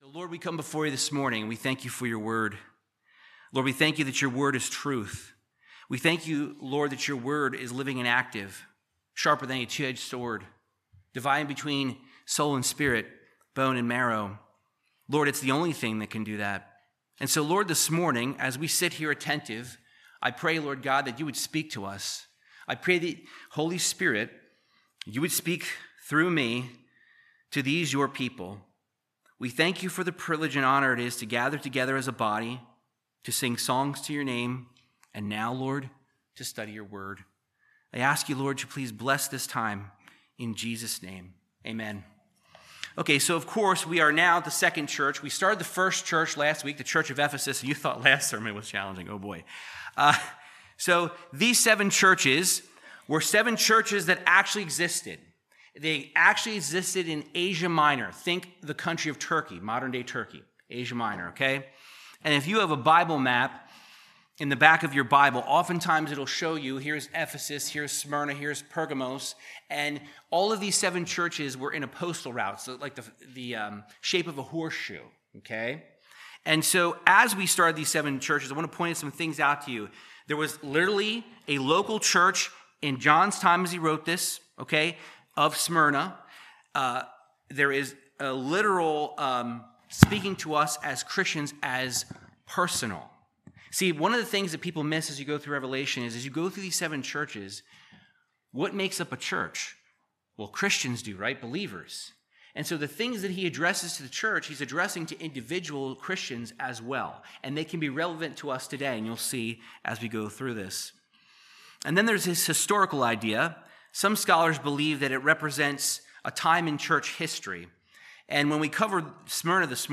Verse by verse Bible teaching through Revelation 2:8-11